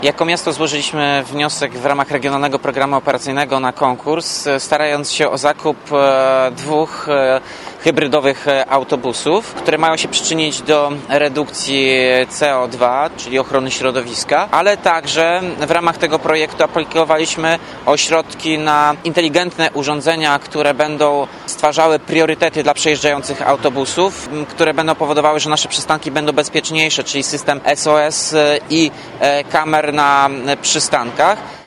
Mówi Tomasz Andrukiewicz, prezydent Ełku: